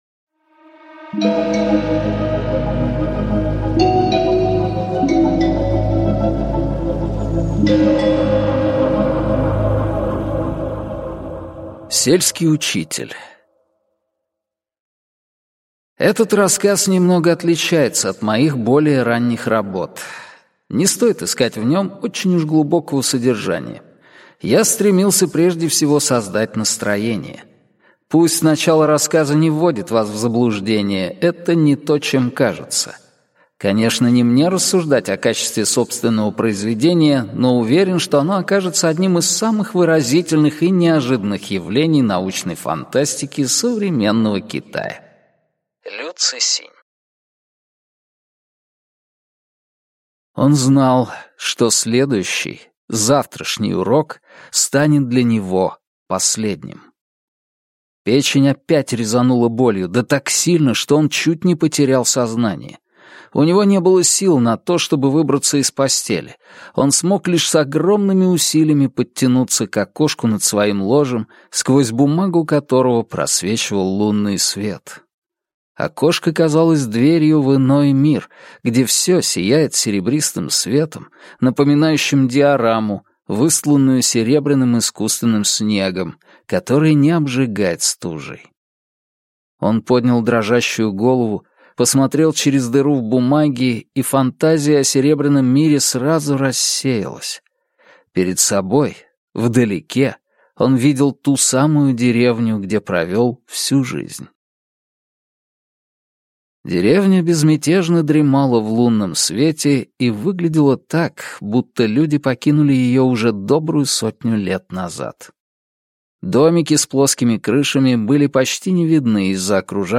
Аудиокнига Удержать небо | Библиотека аудиокниг